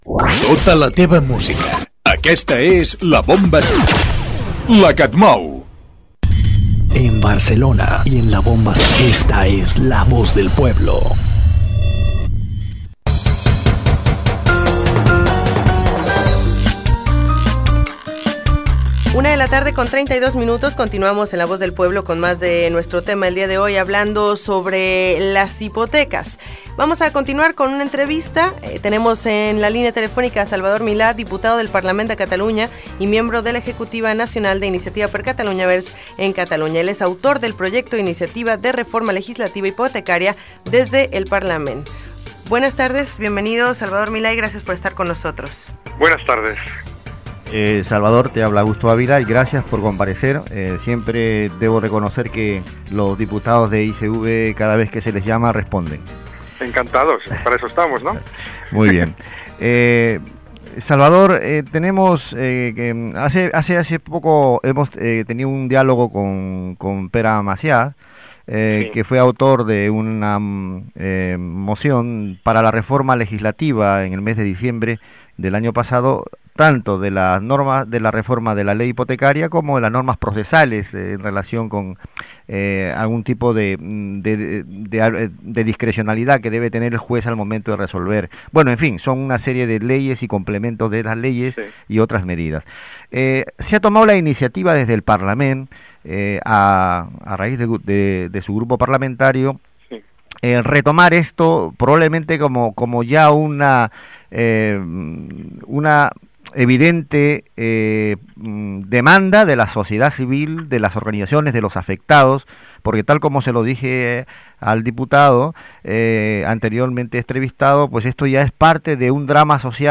Identificació de l'emissora i del programa i entrevista a Salvador Milà, diputat del Parlament de Catalunya i membre de l'executiva nacional d'Iniciativa per Catalunya Verds (ICV). Autor del projecte d'iniciativa de reforma legislativa hipotecària.
Informatiu